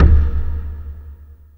SINGLE HITS 0023.wav